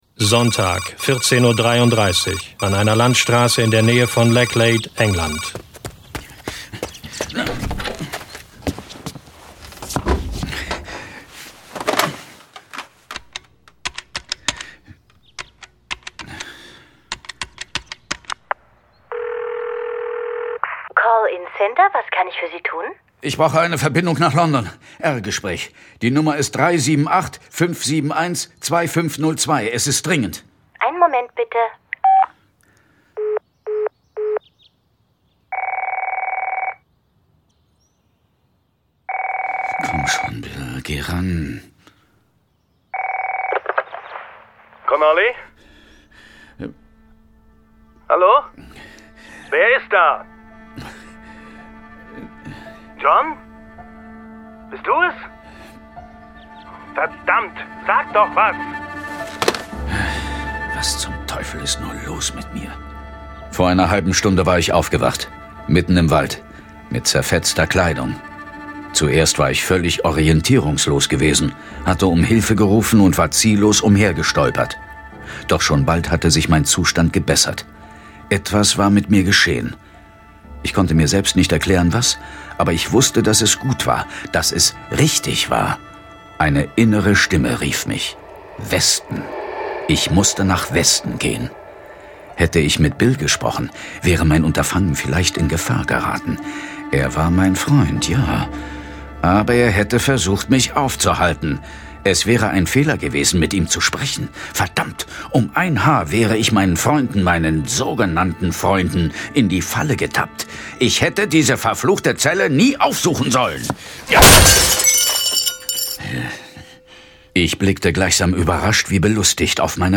Lese- und Medienproben
John Sinclair - Folge 35 Königin der Wölfe. Hörspiel Jason Dark